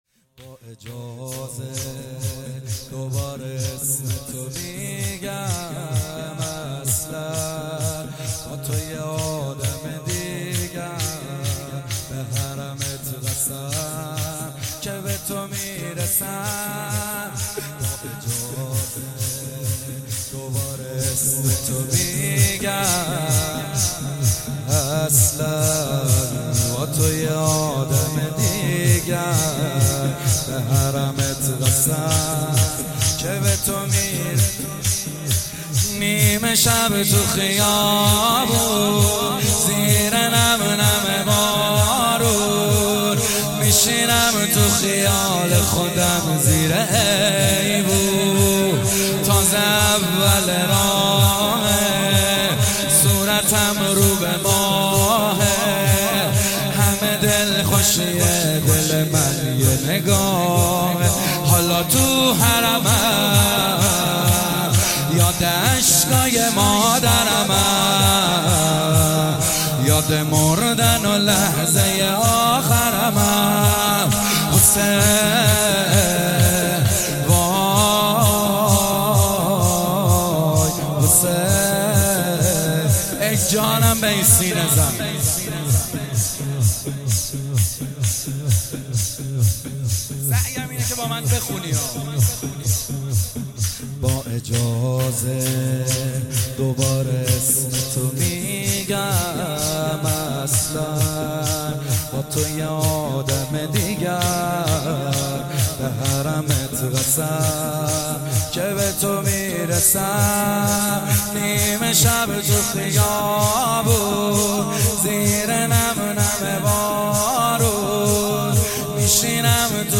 مداحی جدید
مراسم هفتگی حسینیه فاطمة الزهرا (س)